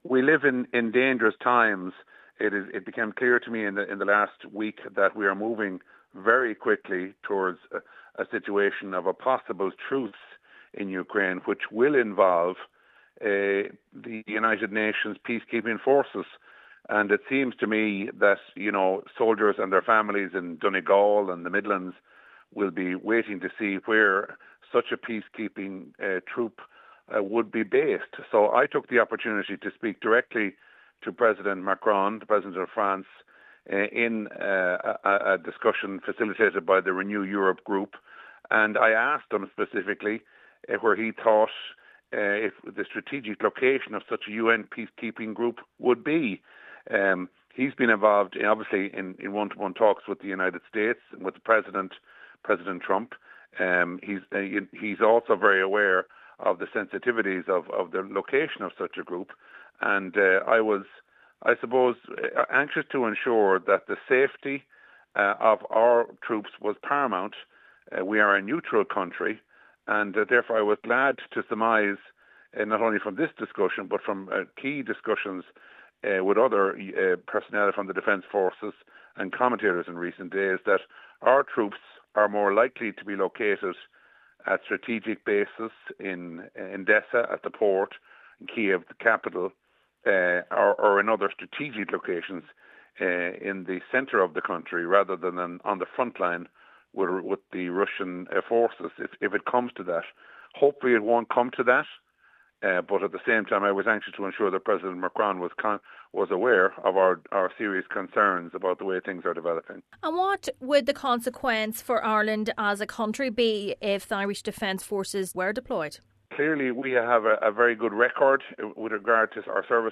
Mr Mullooly says there is great uncertainty over what a future deal between Russia and the US may look like: